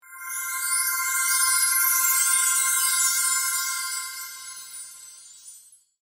magia.mp3